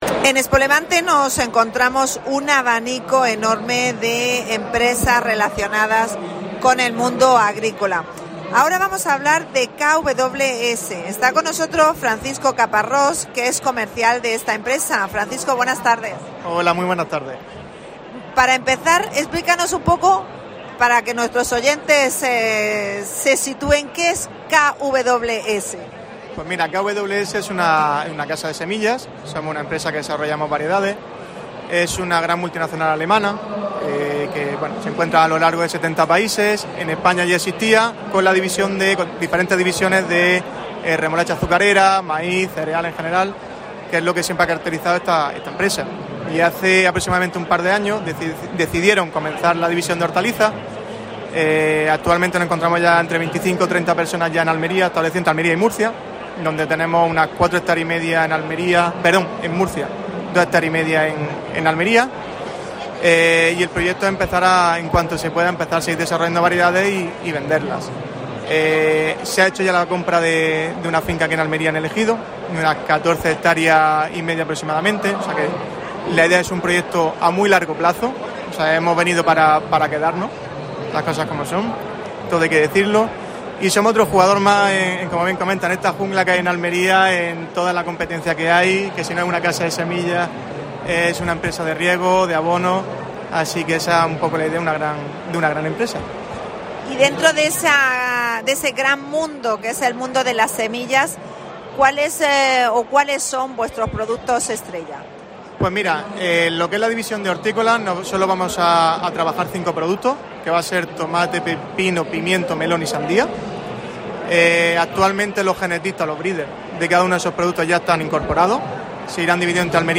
AUDIO: Especial ExpoLevante. Entrevista a responsables de Kws.